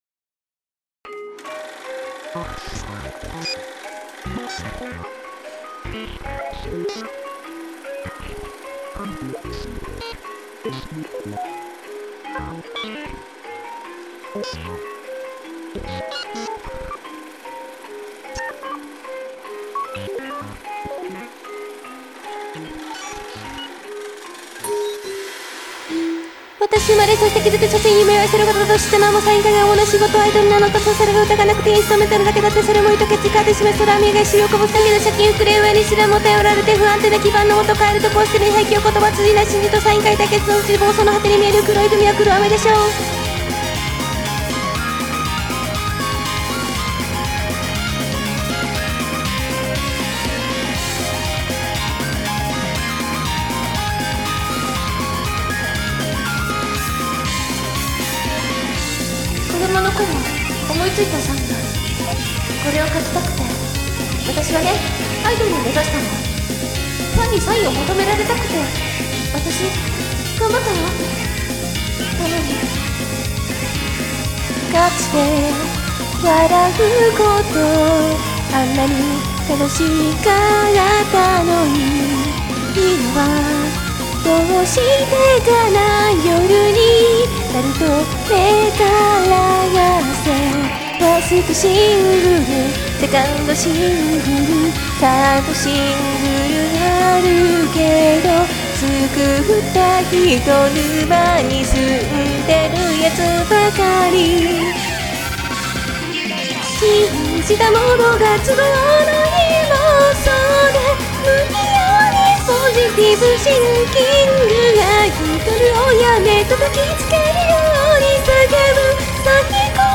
温度差がめちゃくちゃ激しいので注意。